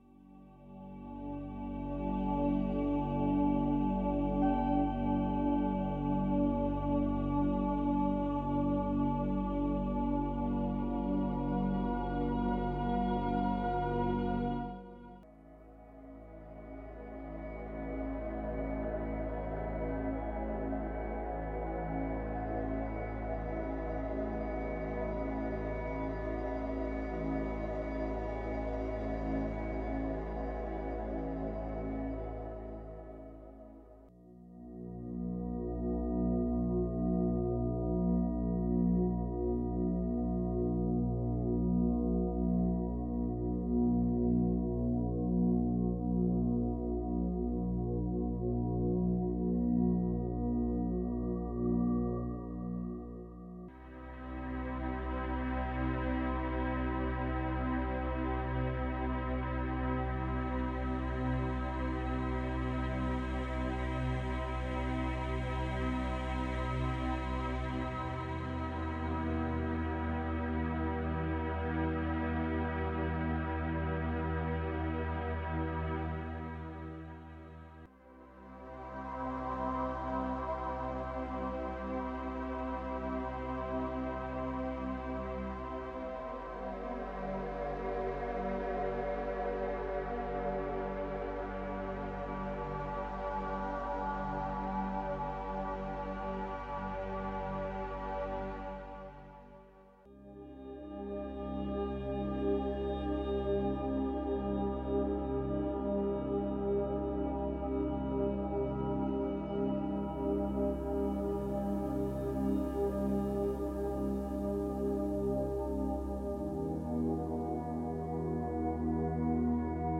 ・メロディの少ないシンプルな音でサウンド瞑想をしたい方